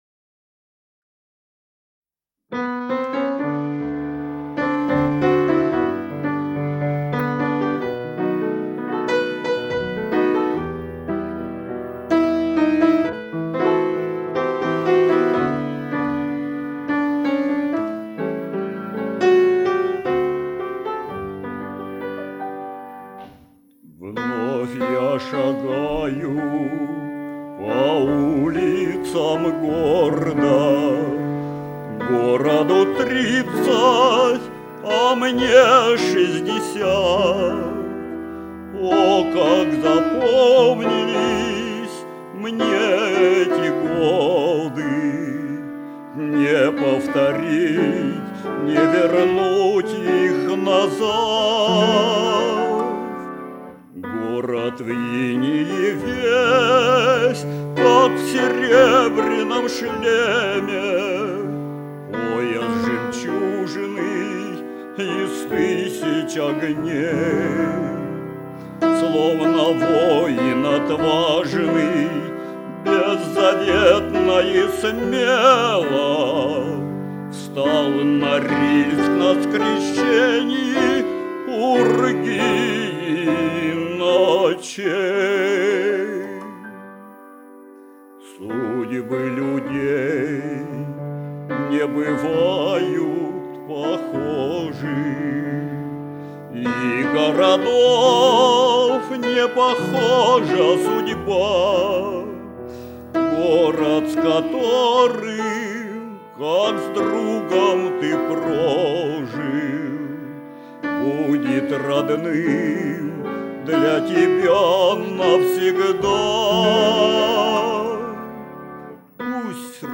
Название передачи Поём, тебя, заполярье Подзаголовок 1 тур и запись концерта снежногорцев Код Н-738 Фонд Норильская студия телевидения Редакция Музыкальная Общее звучание 00:24:25 Дата записи 1971 Дата добавления 05.10.2023 Прослушать